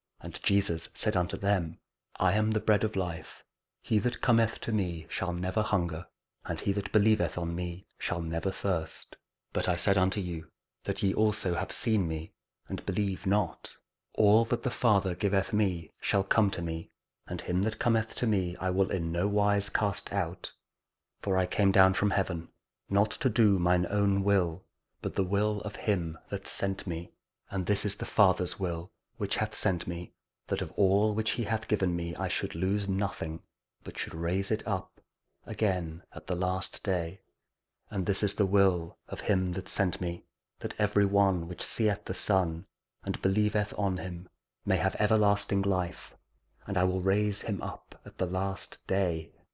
John 6: 35-40 Audio Bible KJV | King James Audio Bible | Daily Verses